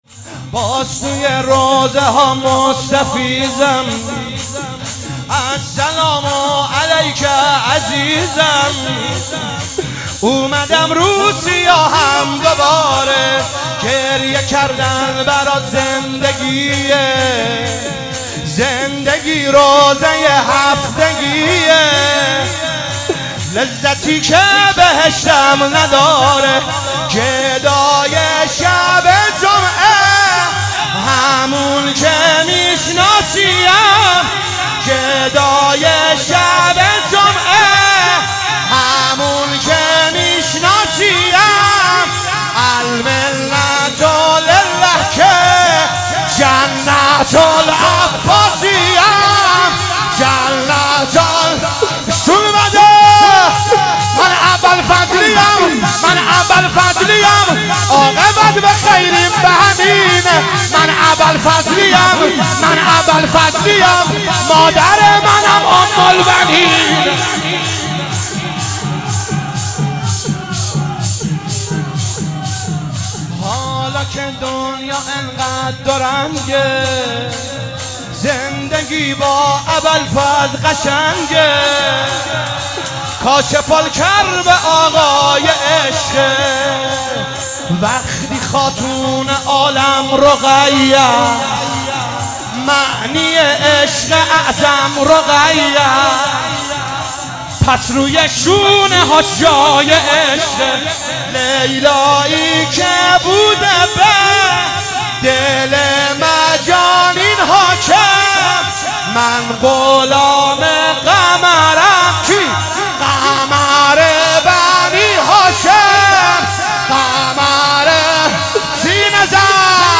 ایام فاطمیه 99 | هیئت جنت العباس(ع) کاشان